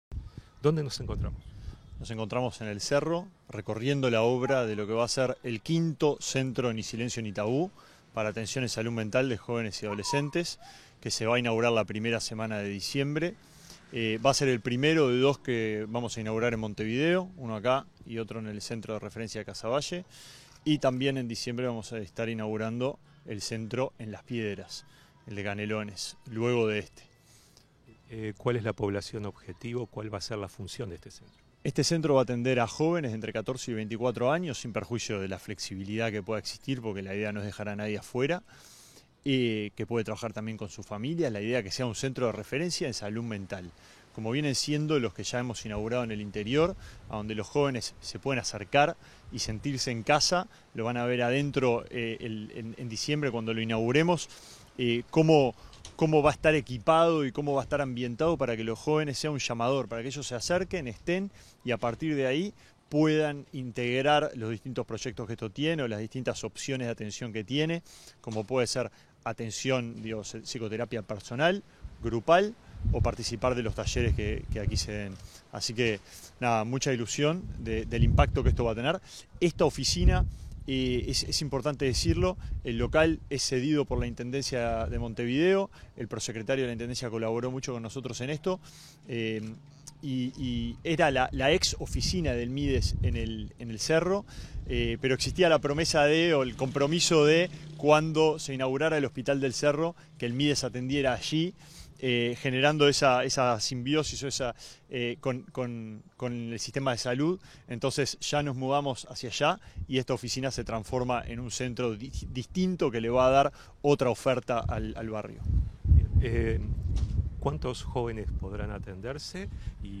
Entrevista al ministro de Desarrollo Social, Alejandro Sciarra